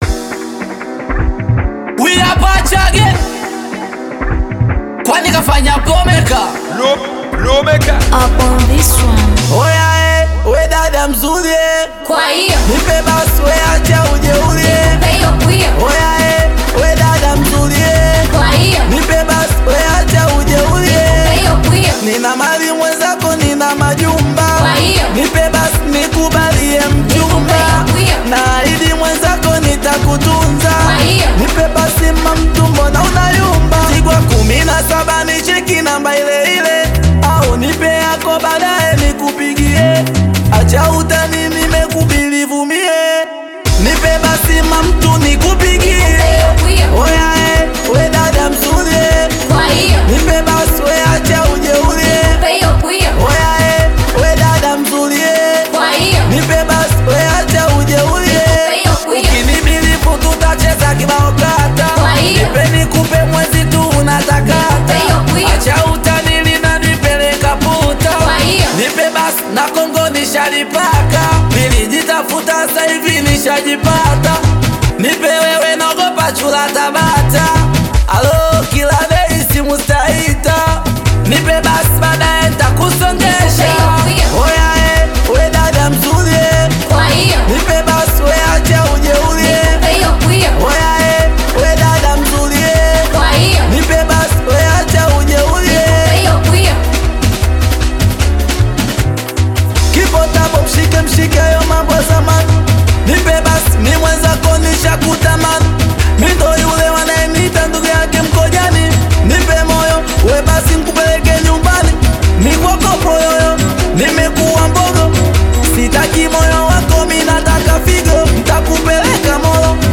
AudioSingeli
is a vibrant Singeli/Afro-dance single